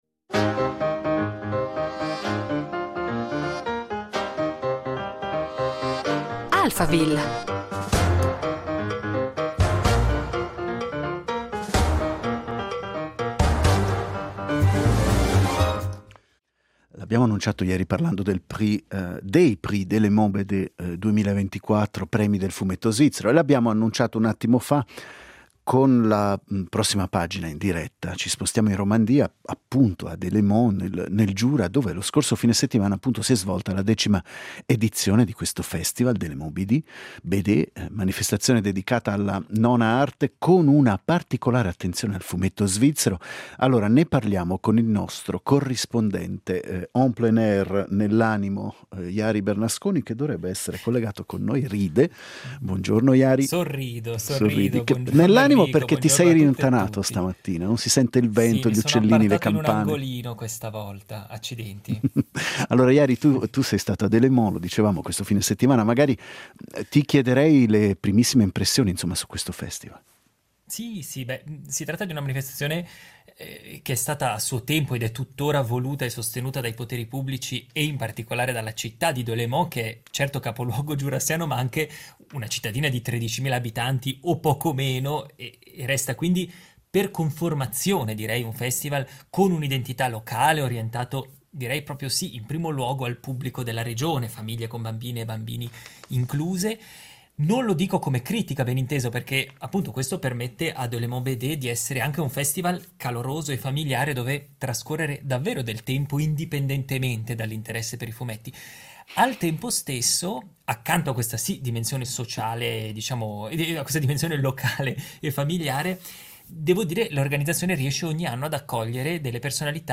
per una intervista a bilancio di questa frequentatissima edizione.